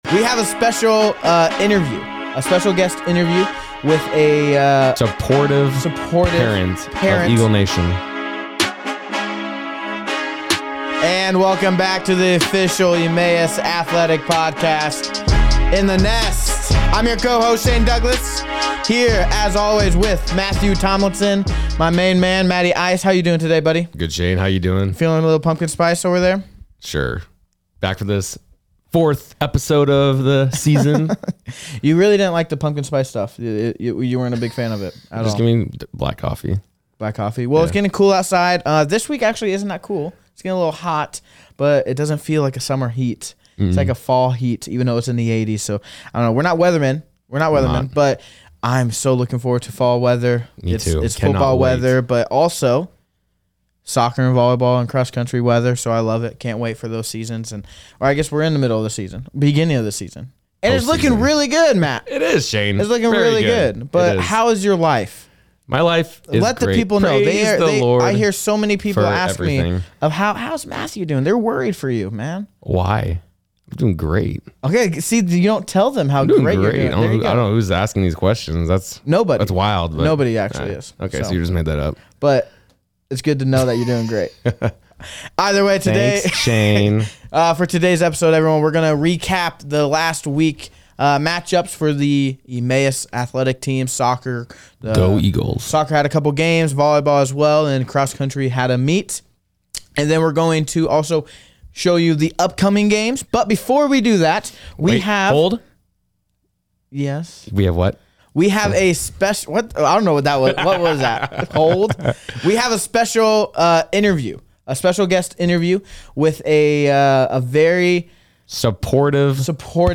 Soccer Rank, Volleyball Sweeps, and Parent interview, Oh My! | In The Nest Season 3 - Episode 4